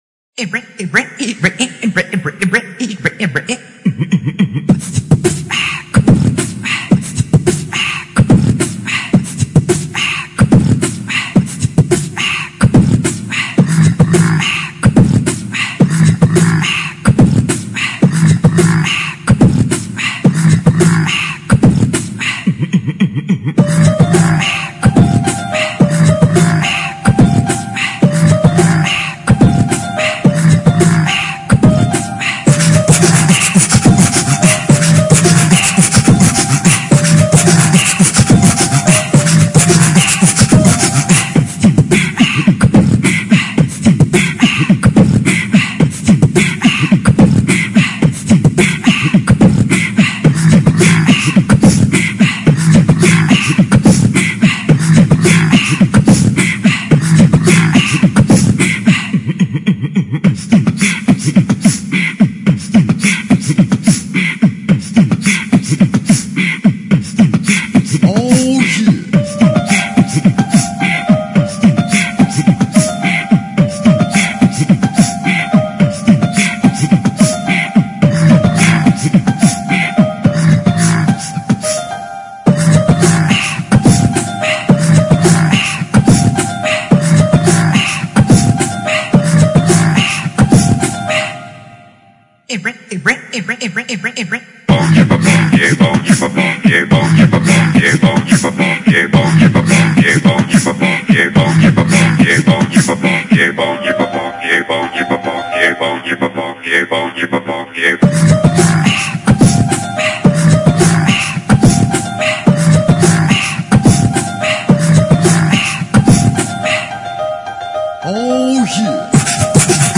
Первый мой BeatBox!!!